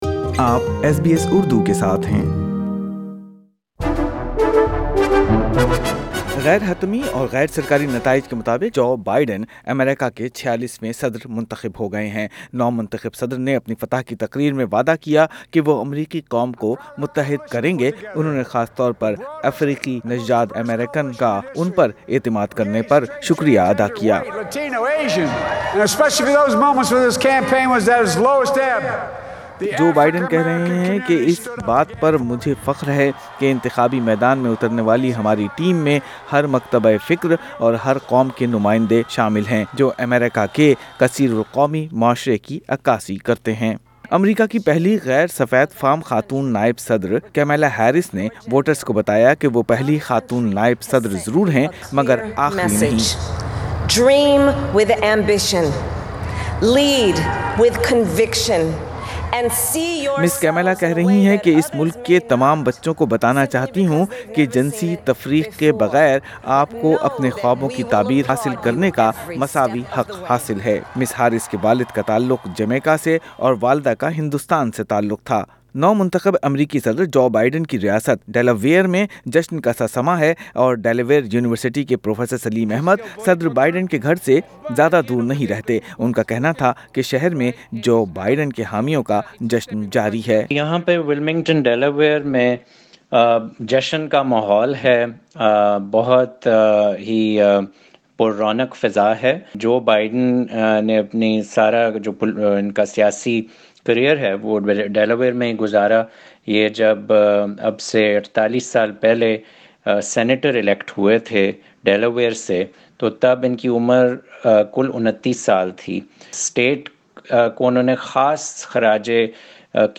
Discussion forum and Talk back - US Election results and its global impact
Participants in the SBS Urdu election forum expressed their views on the US election results. But do recent election results indicate that public opinion in the US is dangerously divided?